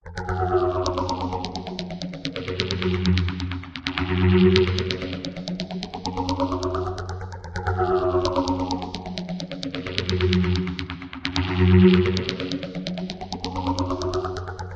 描述：电子奇怪的宇宙环（120 bpm）
Tag: 循环 淡入淡出 电子 怪异 不褪色 宇宙